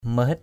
/mə-hɪt/ (đg.) nghe thấy = entendre, percevoir hear, perceive. mahit urang lac hâ khap di lakei mh{T ur/ lC h;% AP d} lk] người ta bảo là con đã yêu....